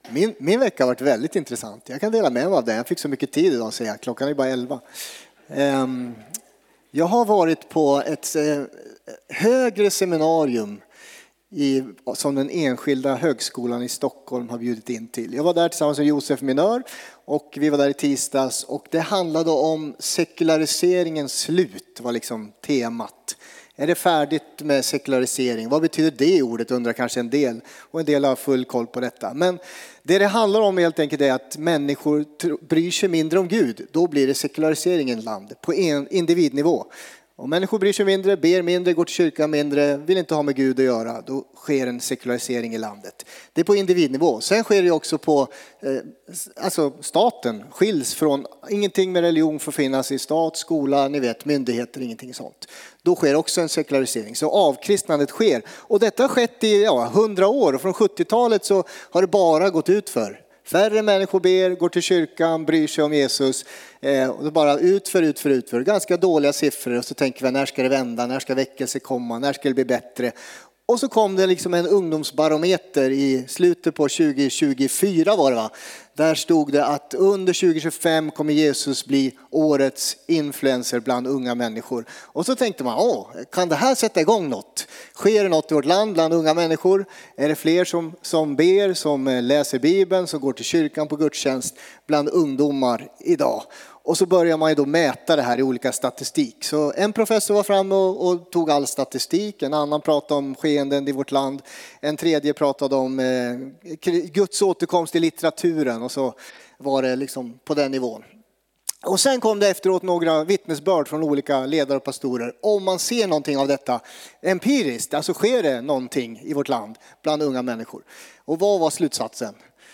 Furuhöjdskyrkan - Predikan